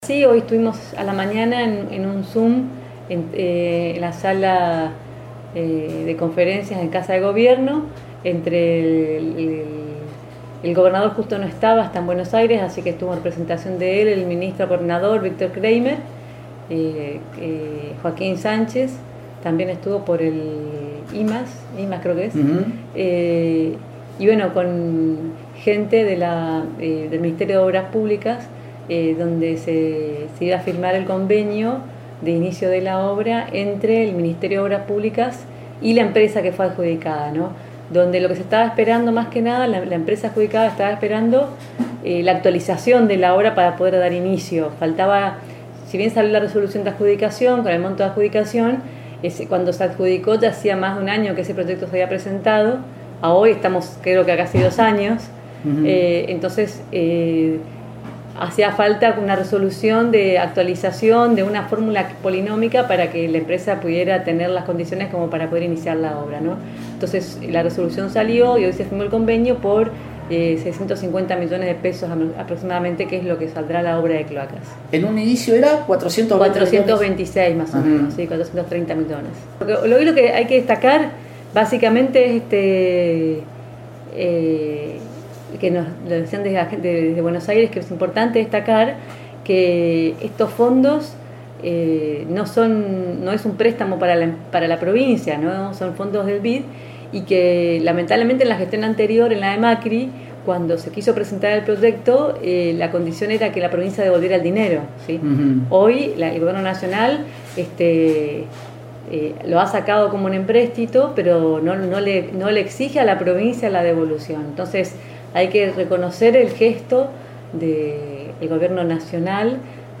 La Intendente María Eugenia Safrán contó que en el día de hoy se firmó el convenio para el arranque de obras de cloacas en Apóstoles, definiendo porque estaba trabada esta firma, costos, duración, solicitudes y, agradeciendo especialmente al Gobierno Nacional y al Gobernador Herrera Ahuad, que hicieron posible esta obra, sin la necesidad de que el Gobierno de la Provincia deba devolver el monto.